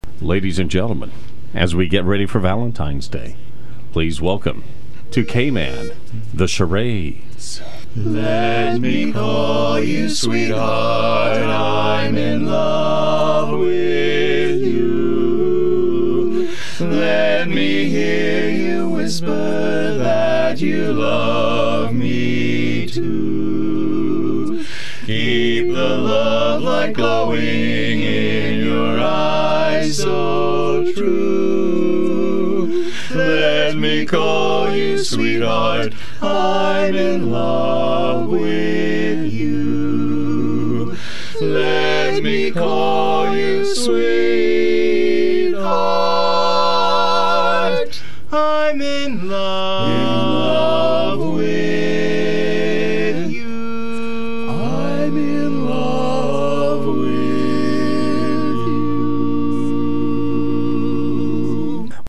Local quartet delivers Valentines spirit